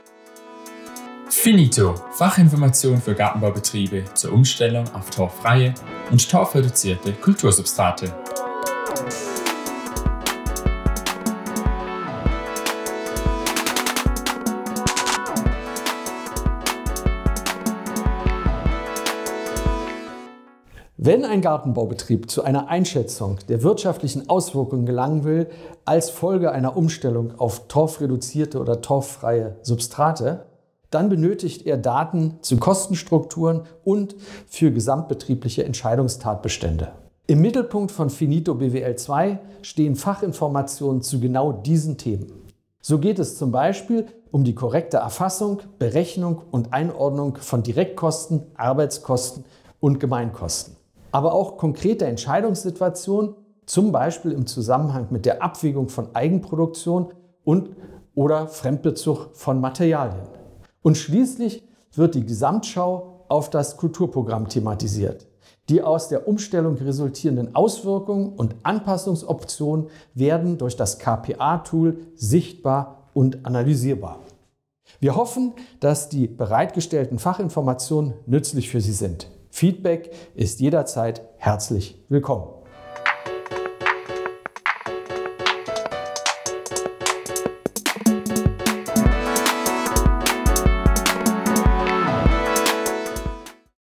Audio-Einführung